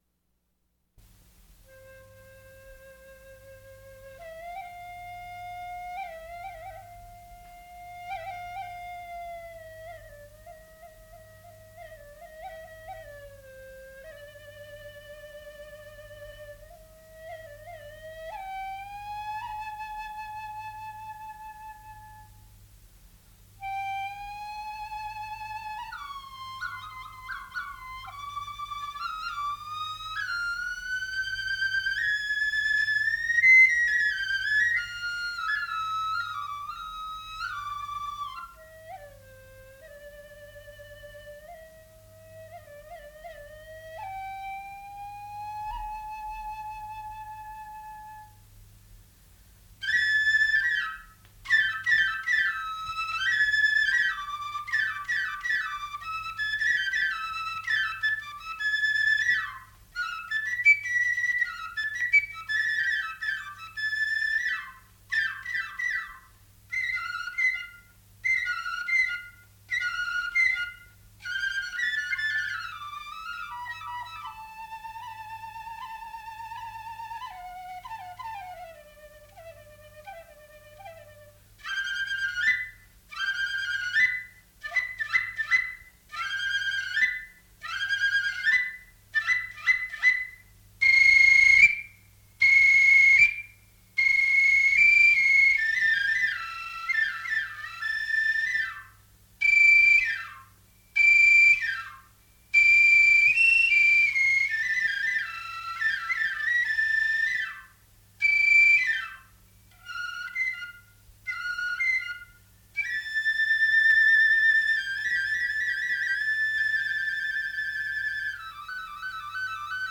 Traditional Vietnamese Music, Click on link to play!
Solo Flute